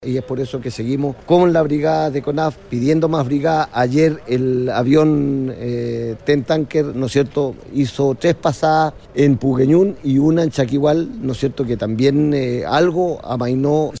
En esta línea el gobernador Fernando Bórquez también resaltó la entrega de estas mascarillas, de modo que se aminoren los efectos del humo presente en las áreas con incendios forestales.